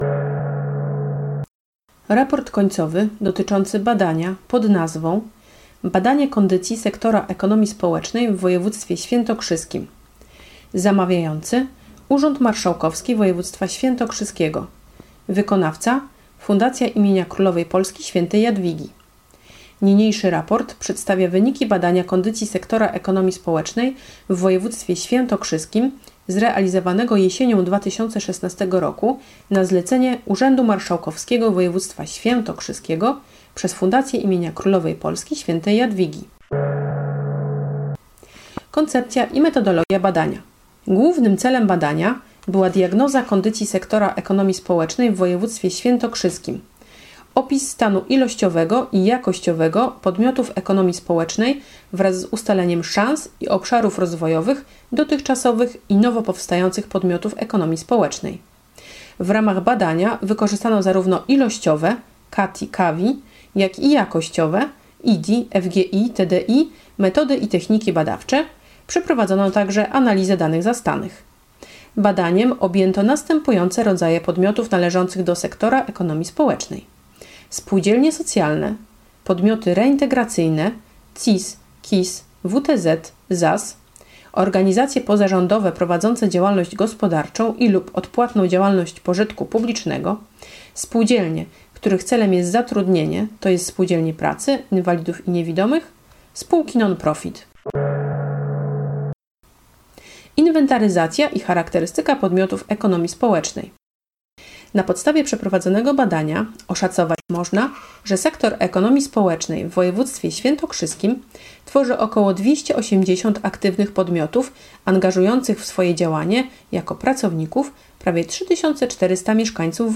Raport końcowy dotyczący badania kondycji sektora ES w woj. Świętokrzyskim (audiobook) Typ: mp3 Rozmiar: 11.32 Mb Dodano: 22 lutego 2017 r. Pobrany: 1018 razy Raport końcowy dotyczący badania kondycji sektora ES w woj.